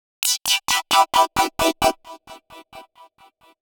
Index of /musicradar/uk-garage-samples/132bpm Lines n Loops/Synths